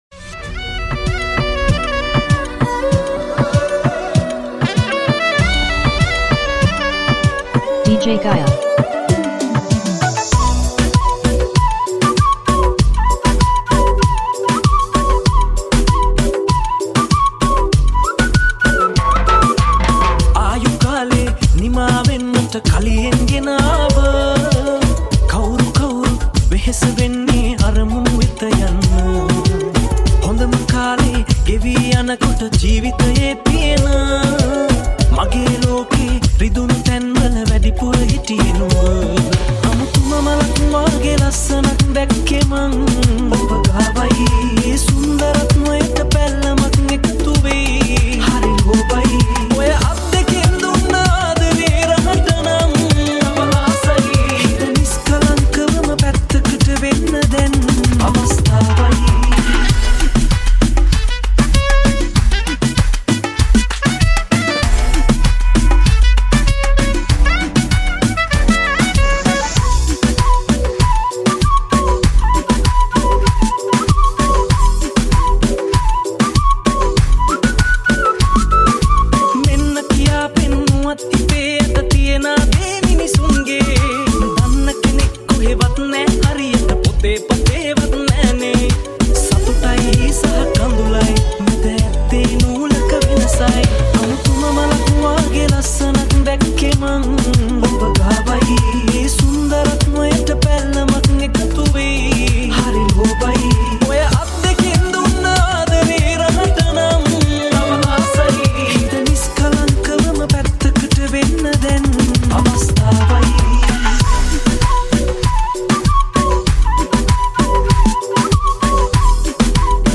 High quality Sri Lankan remix MP3 (4.8).